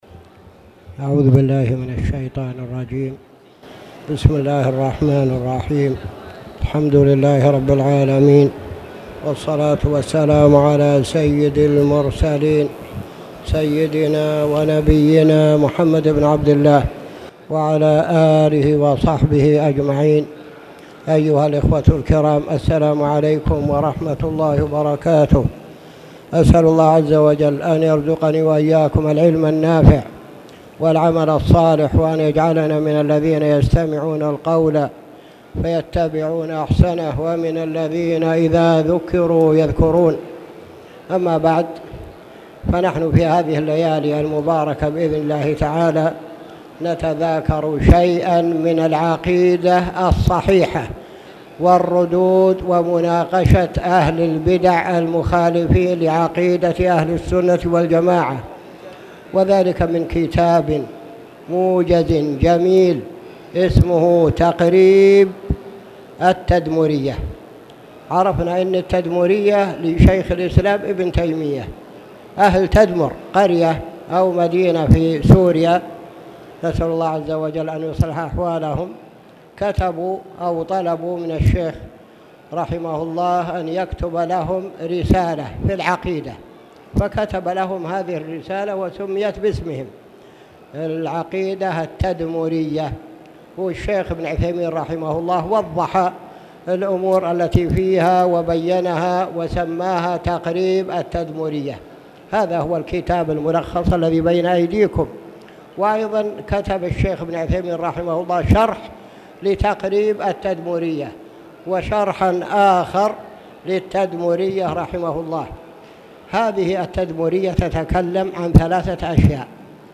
تاريخ النشر ١٦ صفر ١٤٣٨ هـ المكان: المسجد الحرام الشيخ